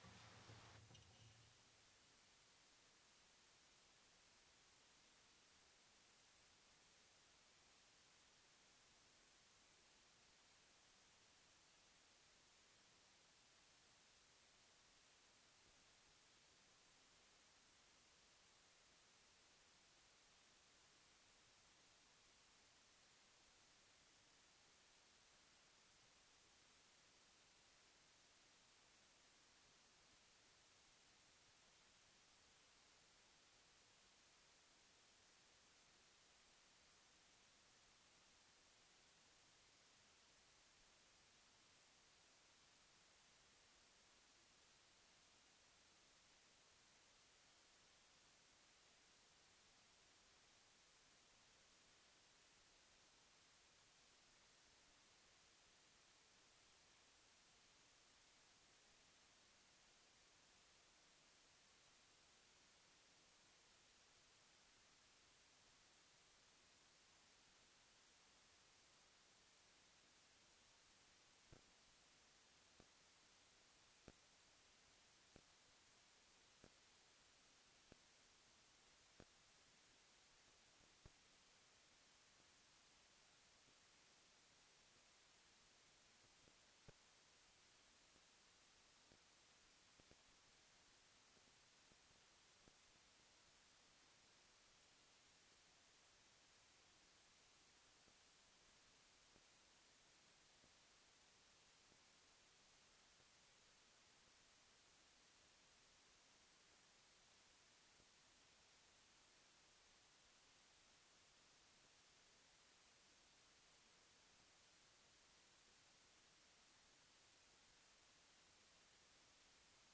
Download Download SERMON DESCRIPTION Jesus calls every Christ follower to be the light of the world, sent into everyday life to reflect His love and truth.